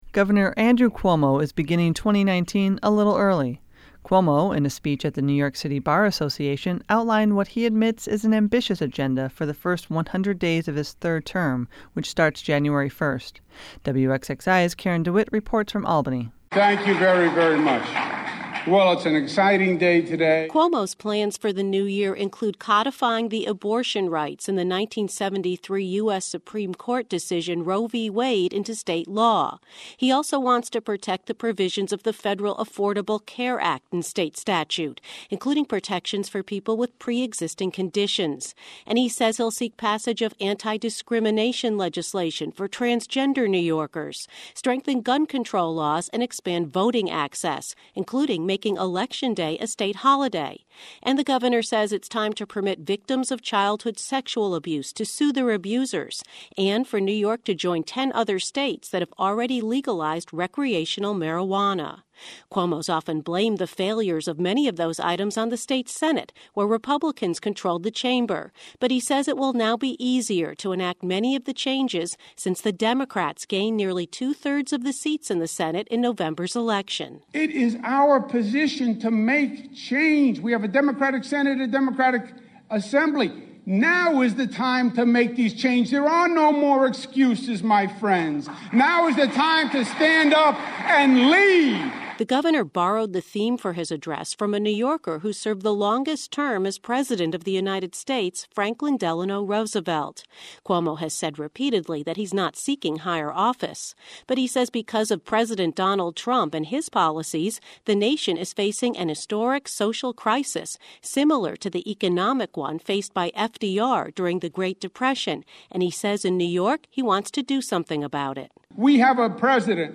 Cuomo, in a speech at the New York City Bar Association, outlined what he admits is an ambitious agenda for the first 100 days of his third term, which starts Jan. 1.
“Now is the time to make these changes, there are no more excuses, my friends,” Cuomo said to applause.